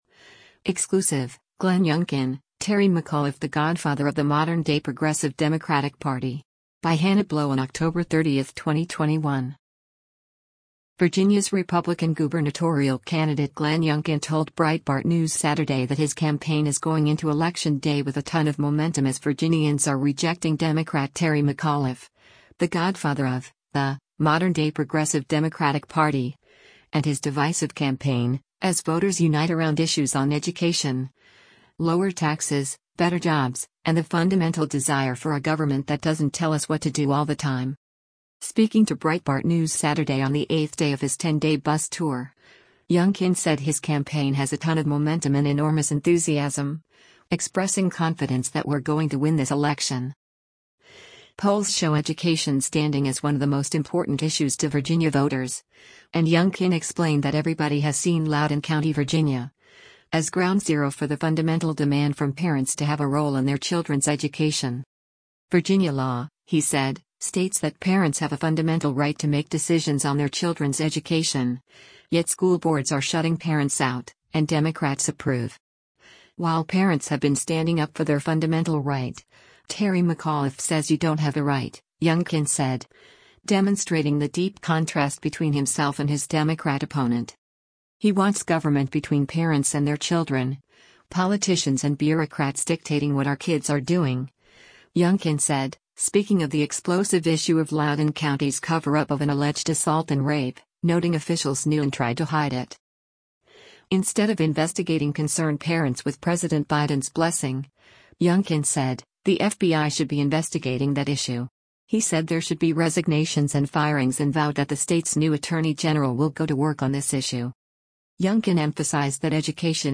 Speaking to Breitbart News Saturday on the eighth day of his ten-day bus tour, Youngkin said his campaign has “a ton of momentum” and “enormous enthusiasm,” expressing confidence that “we’re going to win this” election.